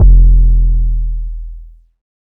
808 Kick 20_DN.wav